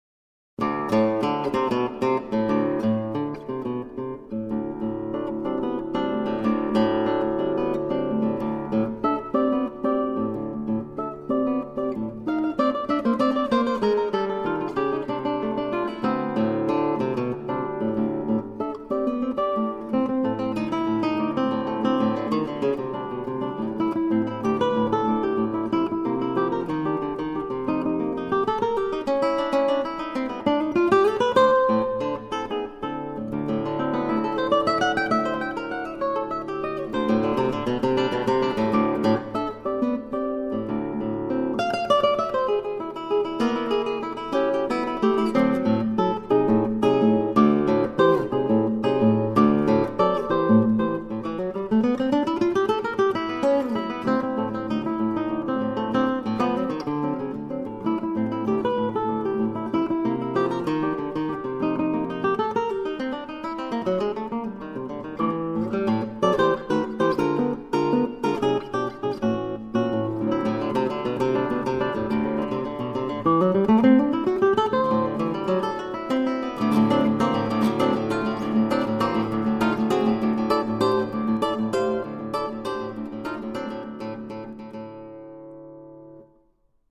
SOLO GUITAR WORKS